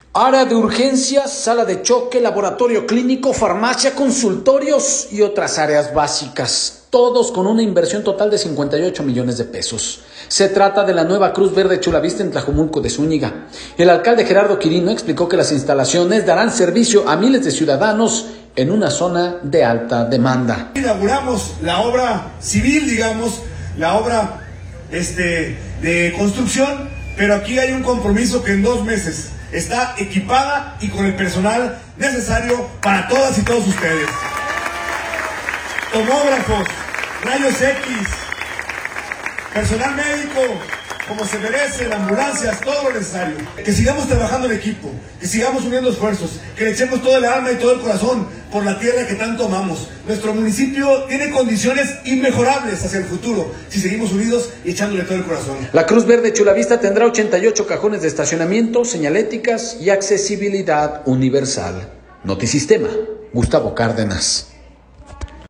Área de urgencias, sala de choque, laboratorio clínico, farmacia, consultorios, y otras áreas básicas, todos con una inversión total de 58 millones de pesos, se trata de la nueva Cruz Verde Chulavista en Tlajomulco de Zúñiga. El alcalde Gerardo Quirino explicó que las instalaciones dará servicio a miles de ciudadanos en una zona de alta demanda.